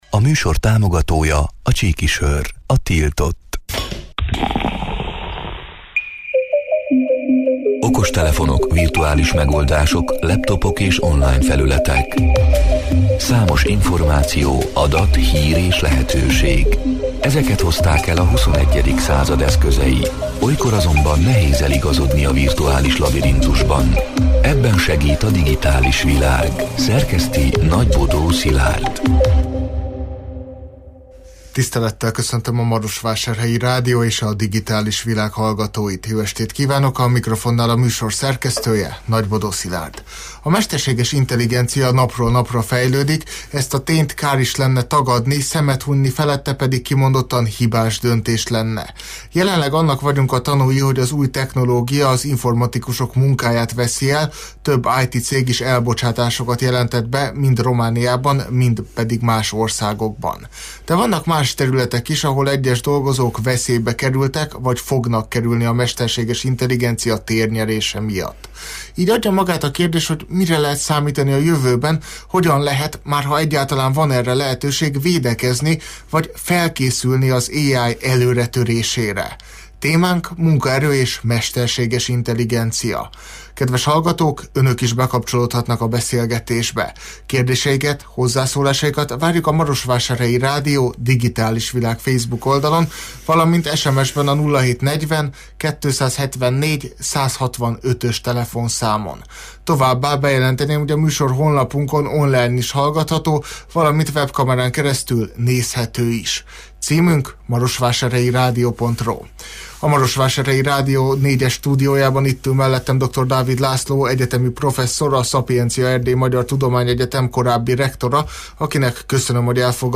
A Marosvásárhelyi Rádió Digitális Világ (elhangzott: 2025. március 11-én, kedden este nyolc órától élőben) c. műsorának hanganyaga: A mesterséges intelligencia napról-napra fejlődik, ezt a tényt kár is lenne tagadni, szemet hunnyi felette pedig kimondottan hibás döntés lenne.